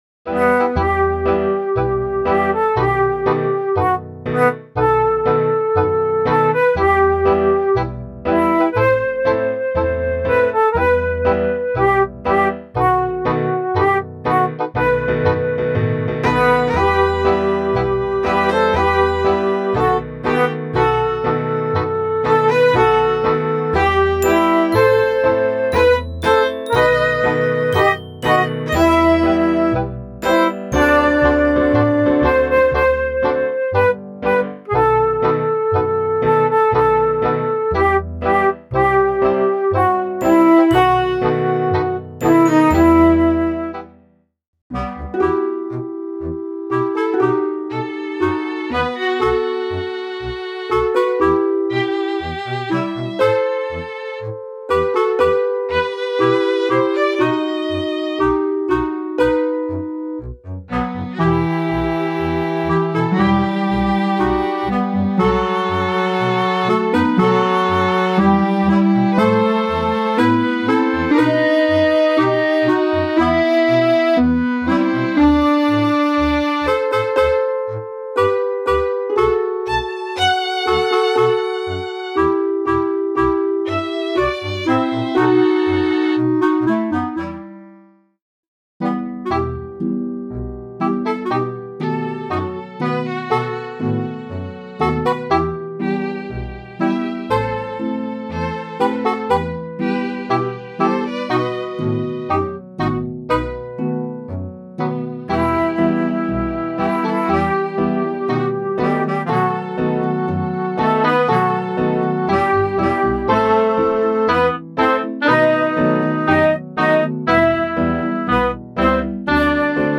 Instrumentalsätze